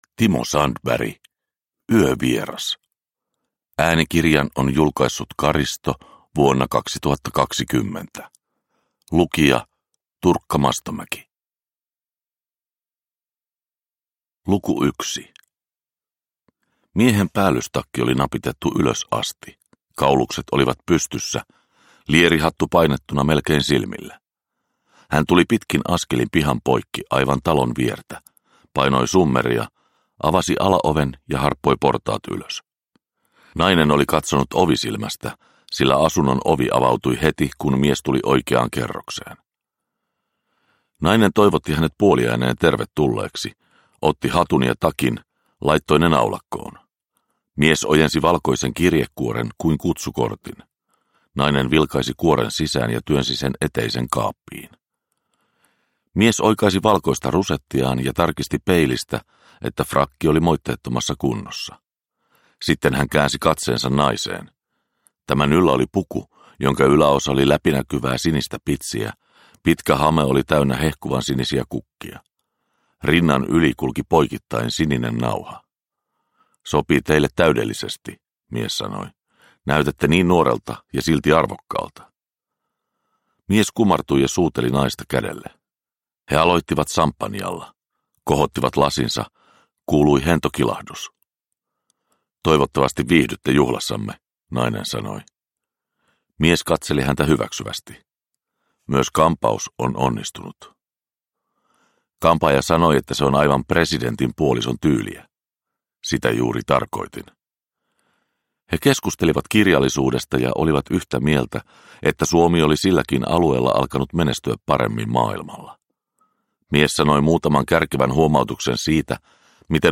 Yövieras – Ljudbok